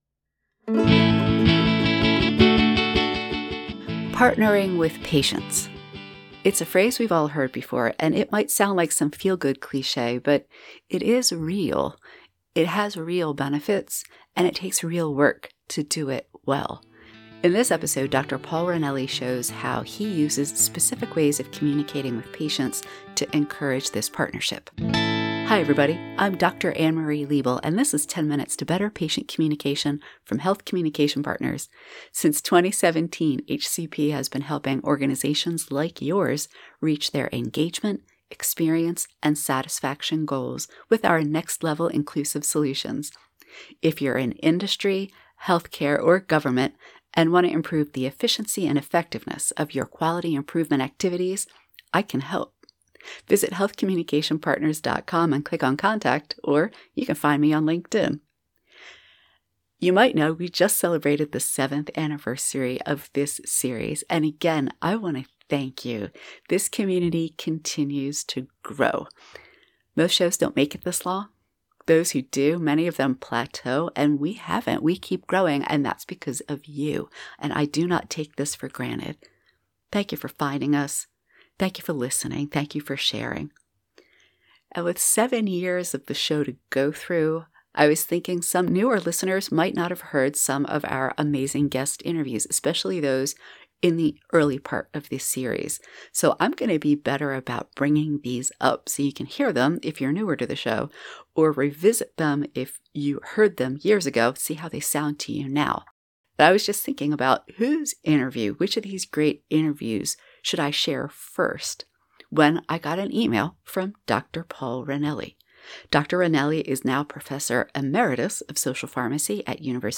This is one of the many interviews you can find in our archives!